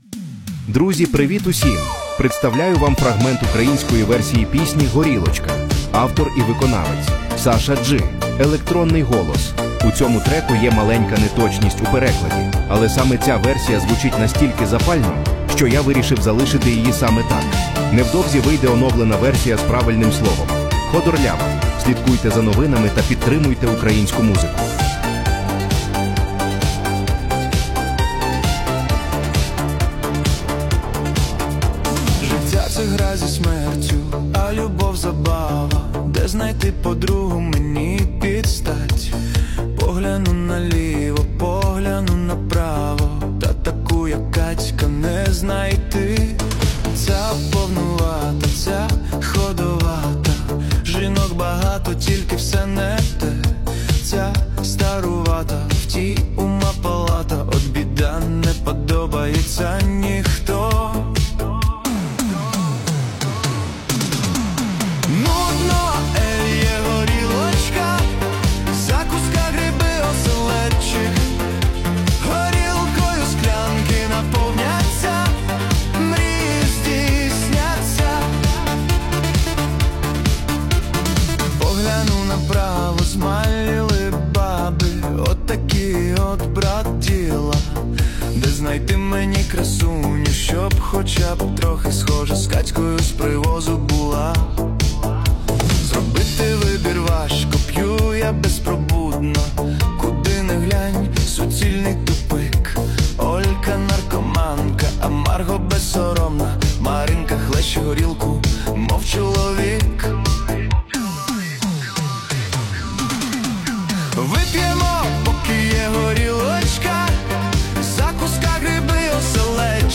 Але саме ця версія звучить настільки запально,